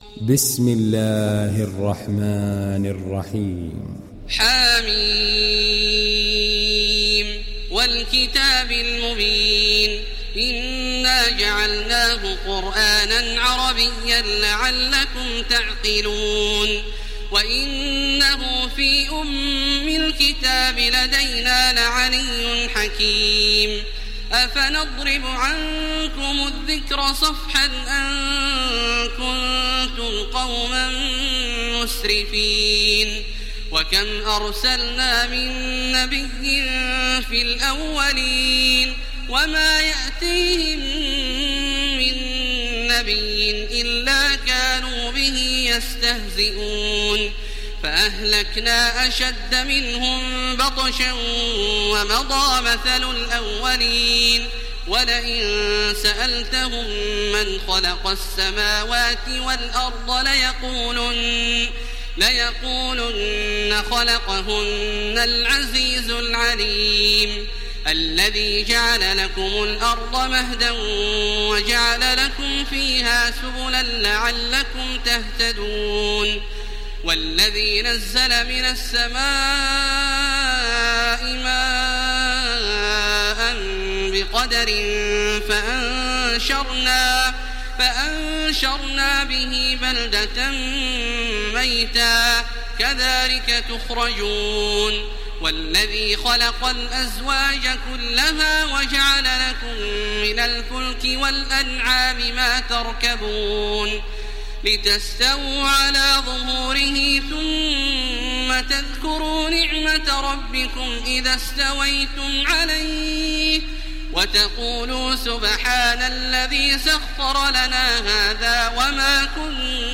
ডাউনলোড সূরা আয-যুখরুফ Taraweeh Makkah 1430